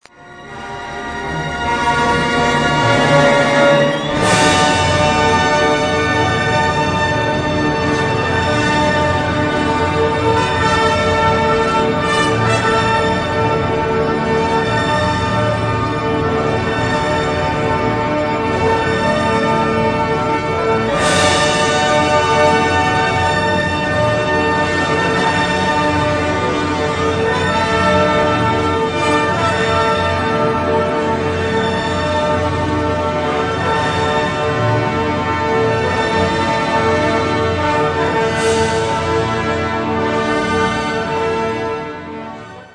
Il s'agit de 2 "tableaux" ou "poèmes symphoniques" dont à vrai dire surtout le deuxième est connu, bien que les musicologues mettent le premier à égalité.
Tableaux impressionnistes pour les fontaines, chacune représentée à un moment particulier de la journée, avec toute l'ambiance qui peut aller avec.
A propos de « tableaux », pourquoi ne pas écouter ces « tableaux symphoniques » en associant la musique à la vue ?